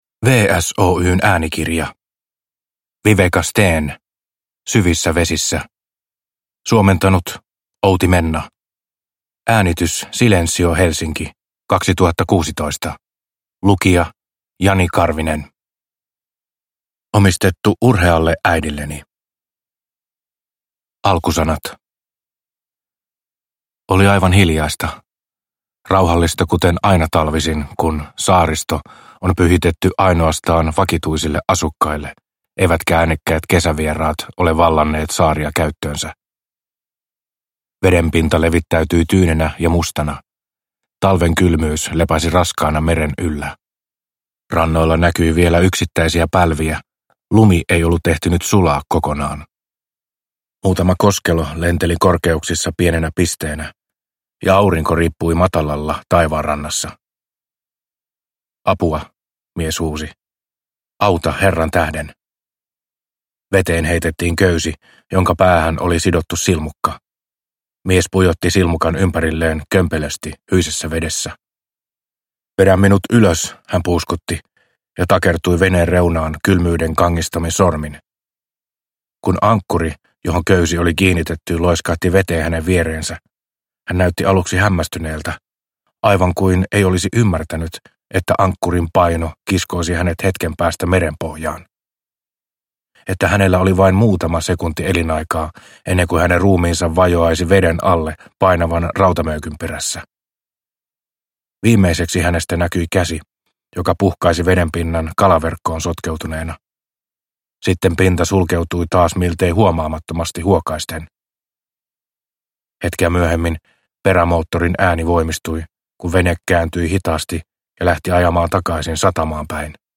Syvissä vesissä – Ljudbok – Laddas ner